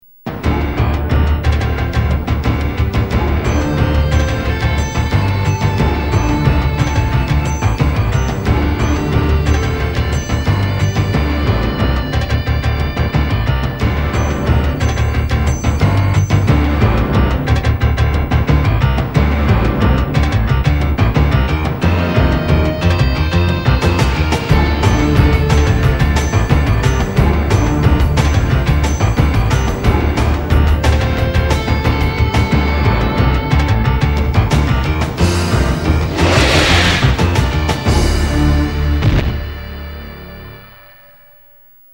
End Credits Theme